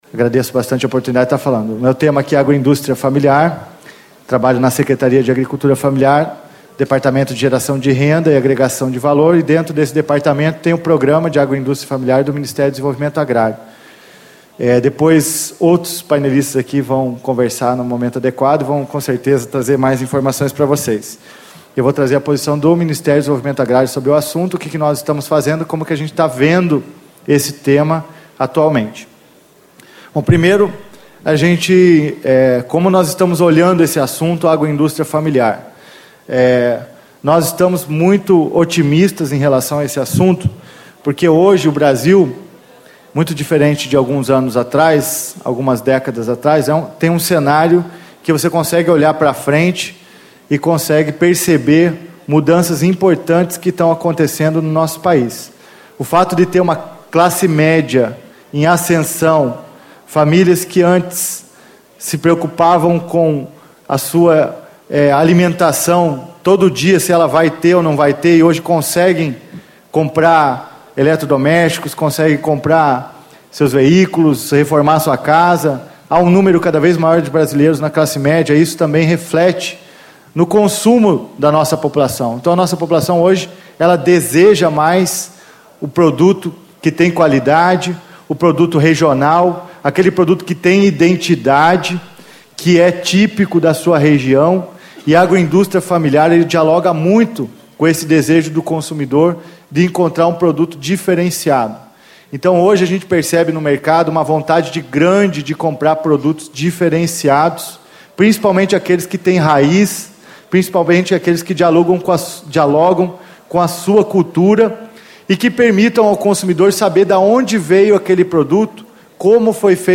Arnoldo Anacleto Campos, Diretor do Departamento de Agregação de Valor e Renda da Secretaria da Agricultura Familiar - Ministério do Desenvolvimento Agrário. Painel: Agroecologia e Sociobiodiversidade
Discursos e Palestras